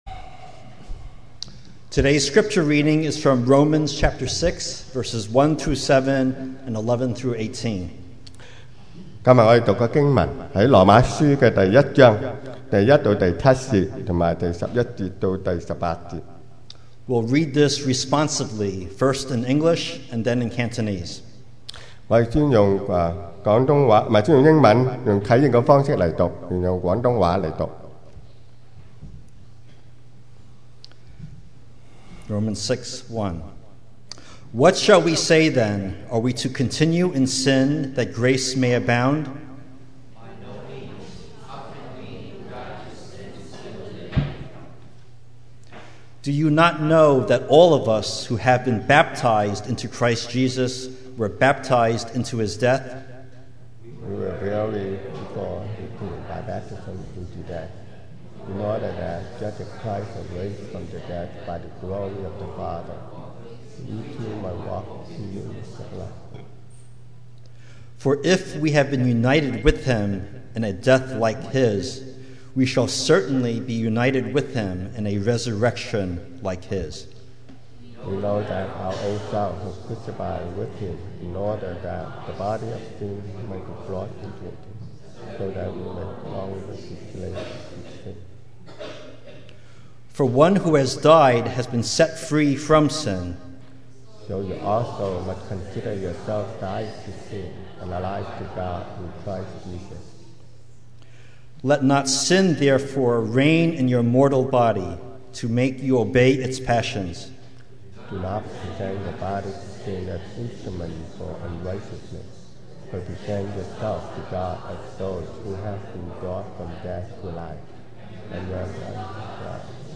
2018 sermon audios
Service Type: Sunday Morning